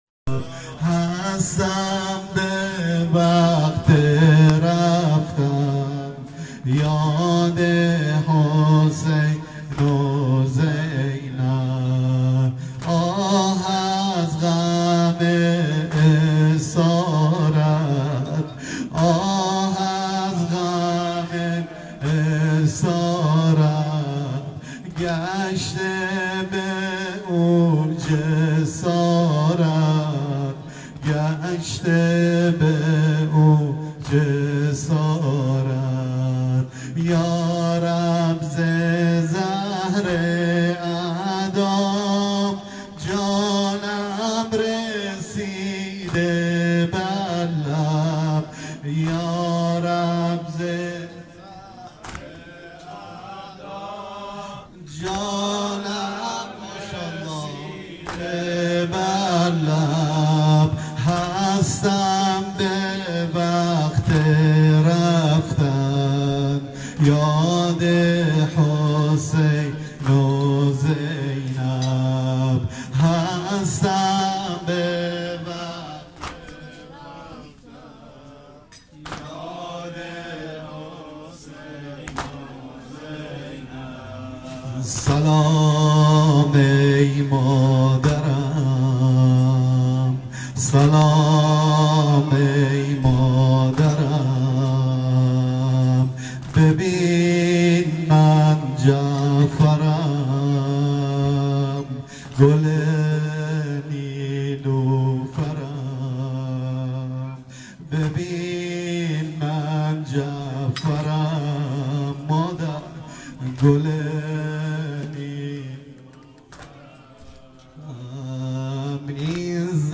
سینه زنی - هستم به وقت محشر